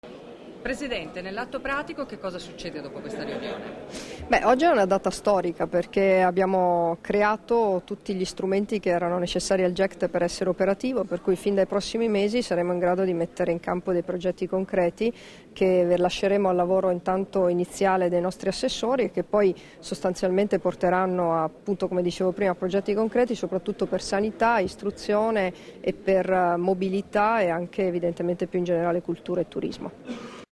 Dichiarazioni di Debora Serracchiani (Formato MP3) [489KB]
rilasciate a margine della II Assemblea del GECT "Euregio Senza Confini", a Venezia il 25 novembre 2013